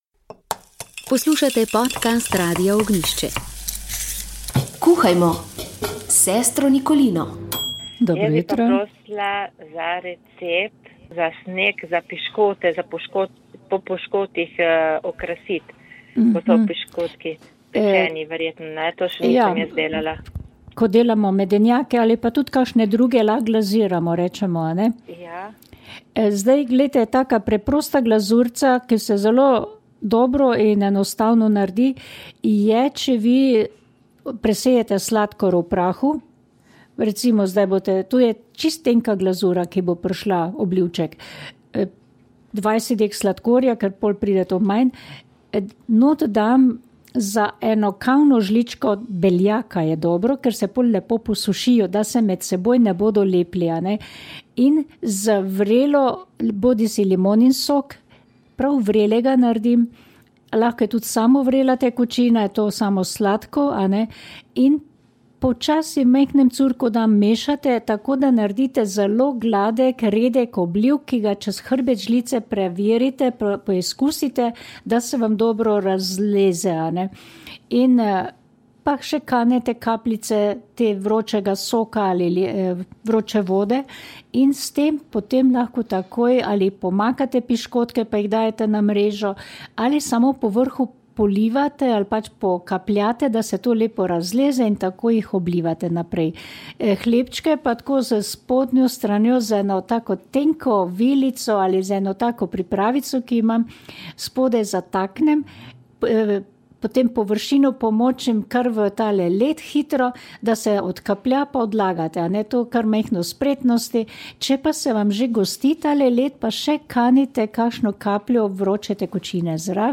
Sv. maša iz župnijske cerkve sv. Jožefa in sv. Barbare iz Idrije 27. 11.
Sv. mašo je ob ljudskem petju